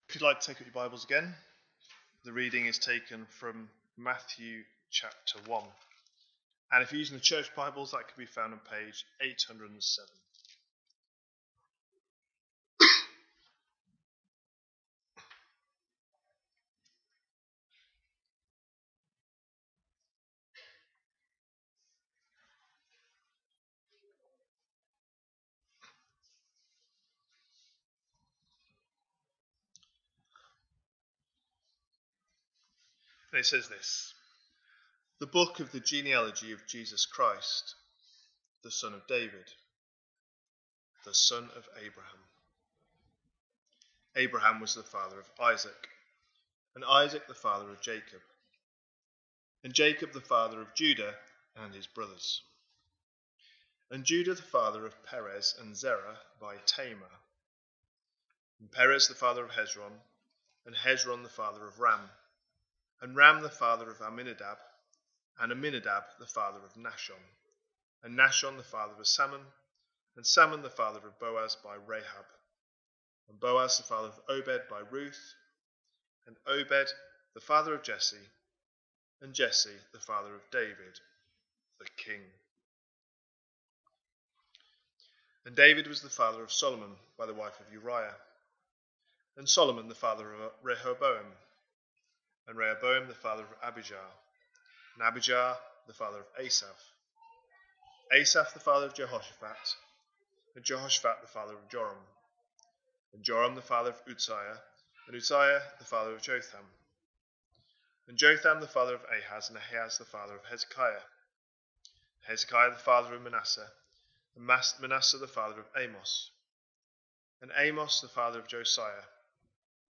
A sermon preached on 15th December, 2024, as part of our Christmas 2024 series.